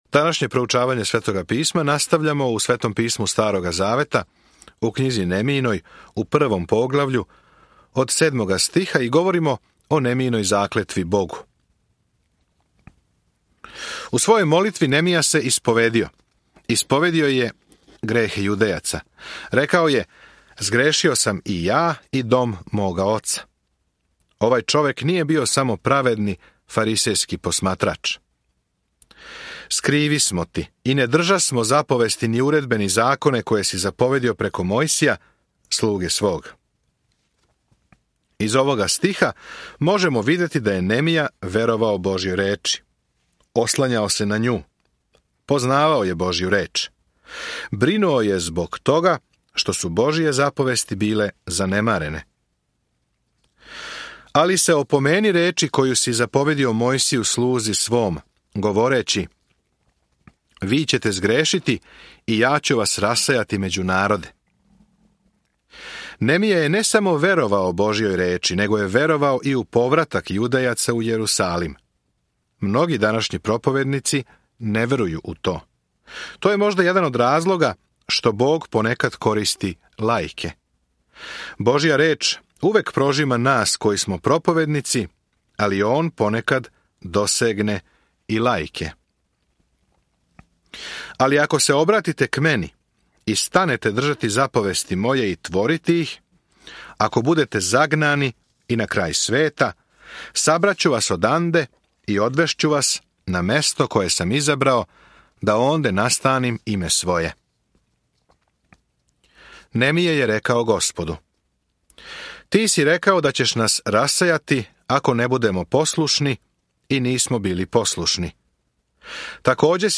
Sveto Pismo Knjiga Nemijina 1:7-11 Knjiga Nemijina 2:1-16 Dan 1 Započni ovaj plan Dan 3 O ovom planu Када се Израел врати у своју земљу, Јерусалим је у лошем стању; обичан човек, Немија, поново гради зид око града у овој последњој историјској књизи. Свакодневно путујте кроз Нехемију док слушате аудио студију и читате одабране стихове из Божје речи.